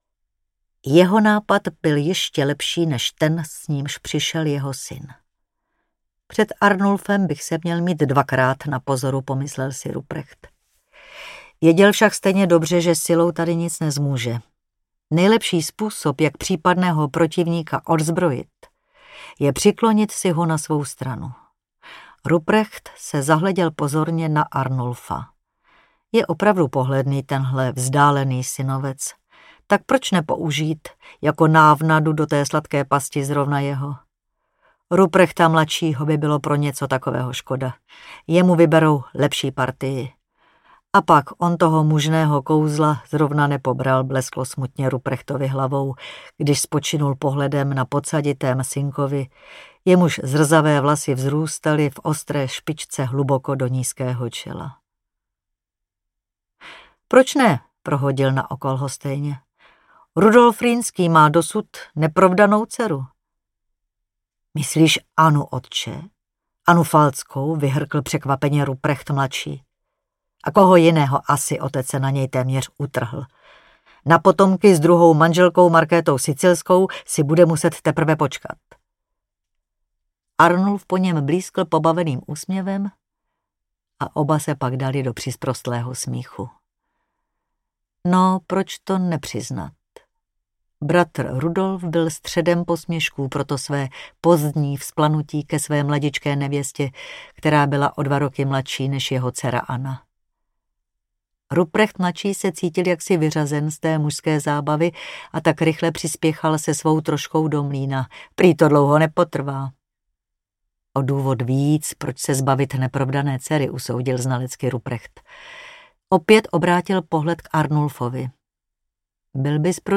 Anna Falcká audiokniha
Ukázka z knihy
• InterpretMilena Steinmasslová